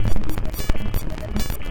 RI_ArpegiFex_140-05.wav